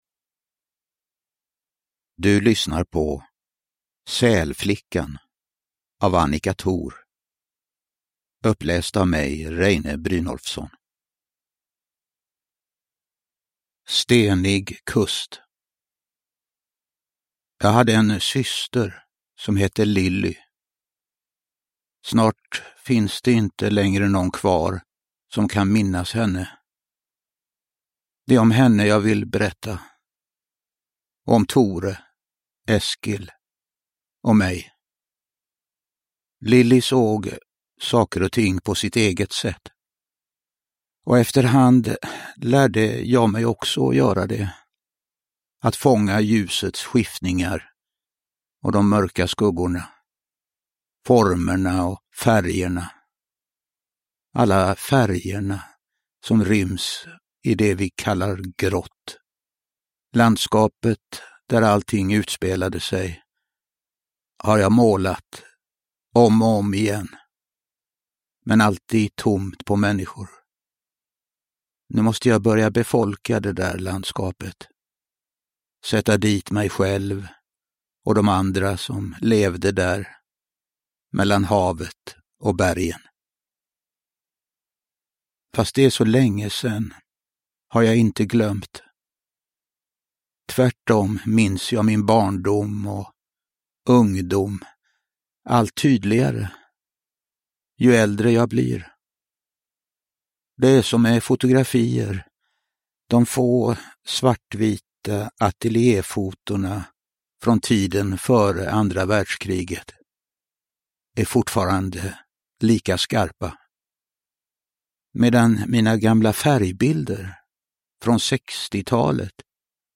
Sälflickan – Ljudbok
Uppläsare: Reine Brynolfsson